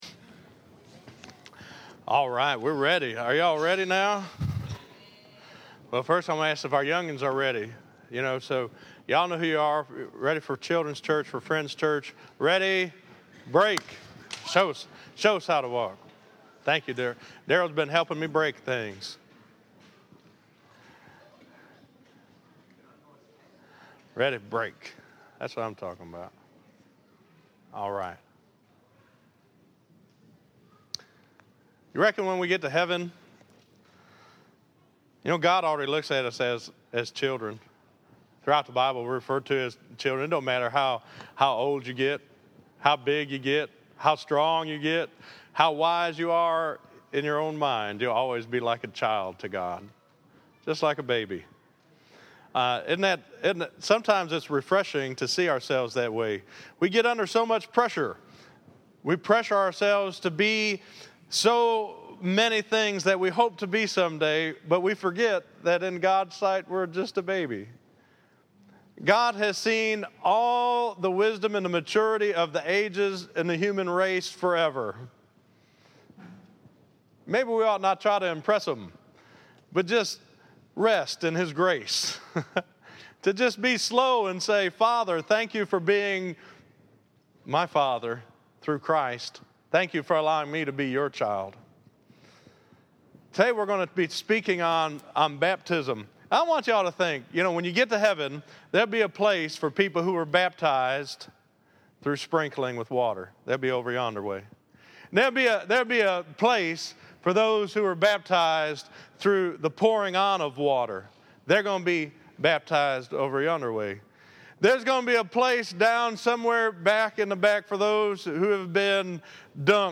Listen to Baptism and Belonging The Bride Of Christ - 09_28_14_Sermon.mp3